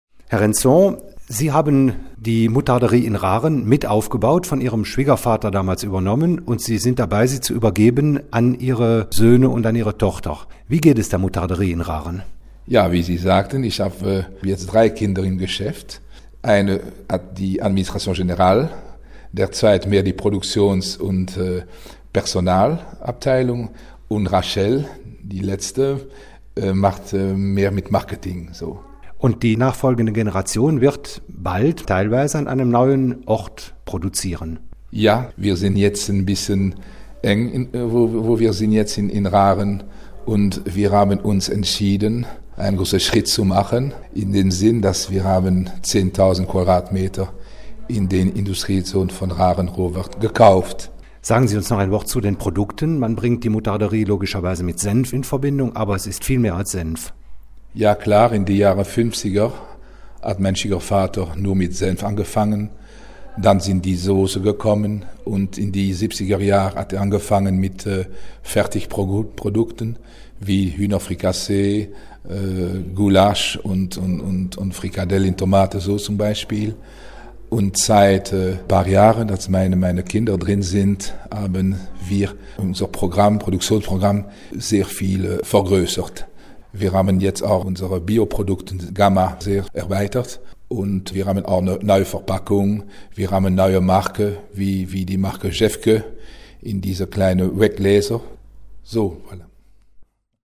Das ausführliche Interview war gestern Seite Vier im GrenzEcho und kann auch heute noch in der E-Paper online nachgelesen werden.